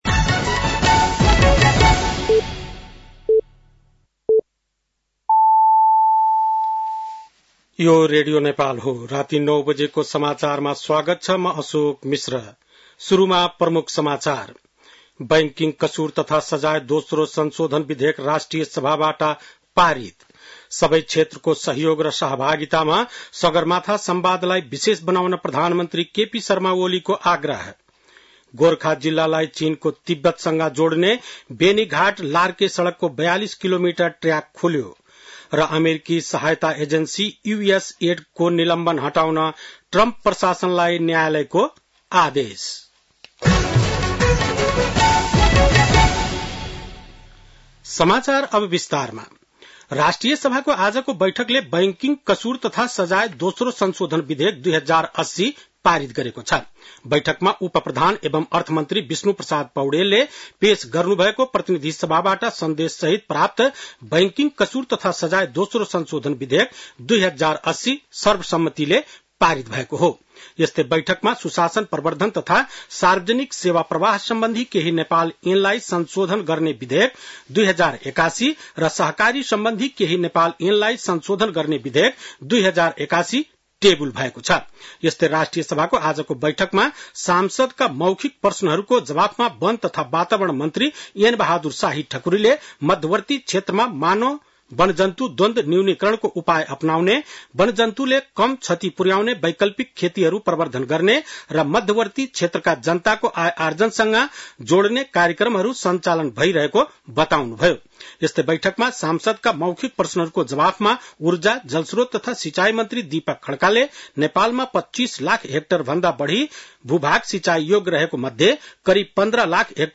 बेलुकी ९ बजेको नेपाली समाचार : ६ चैत , २०८१